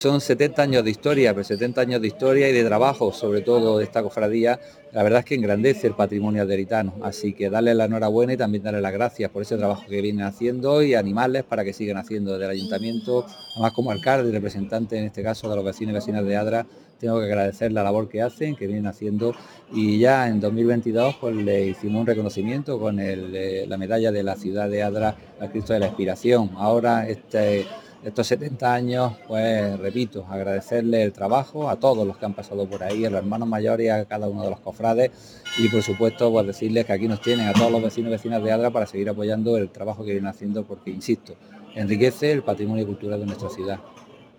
El acto se ha celebrado tras la misa por el 70 aniversario de la Hermandad y ha contado con la participación del presidente provincial y del alcalde del municipio
08-03_adra_alcalde.mp3